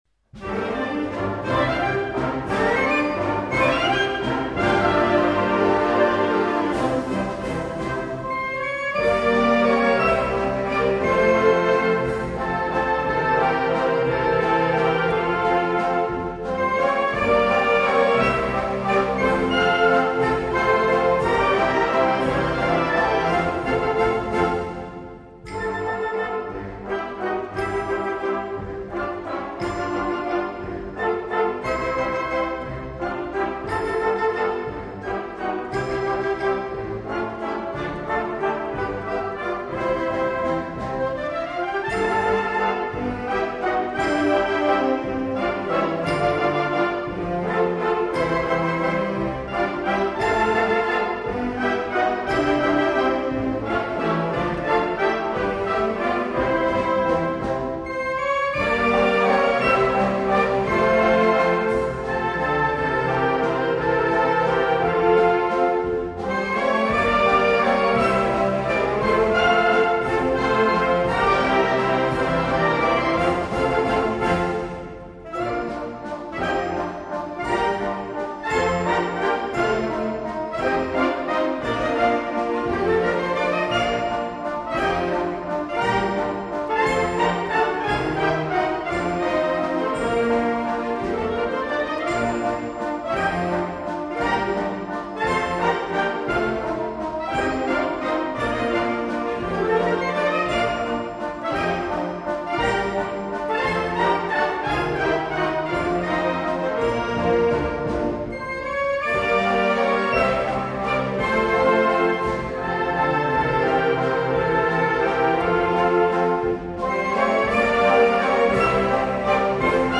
Per orchestra
in una strumentazione per orchestra sinfonica